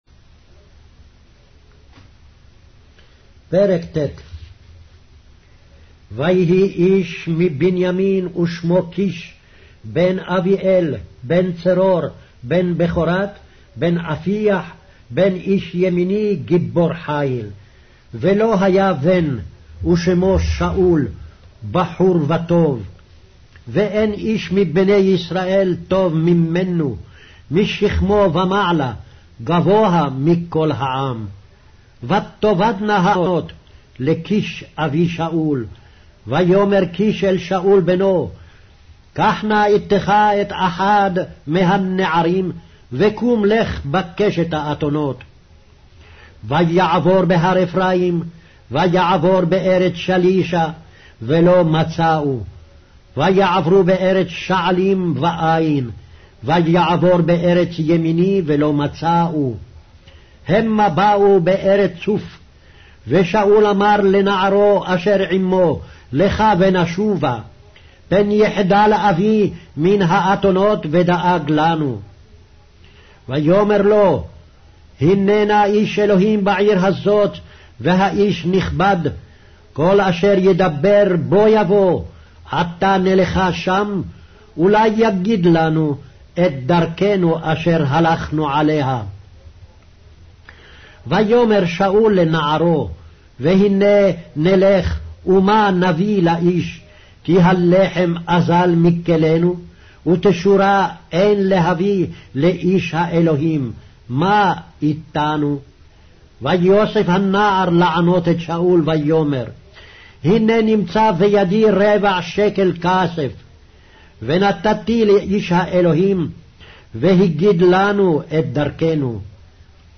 Hebrew Audio Bible - 1-Samuel 15 in Mov bible version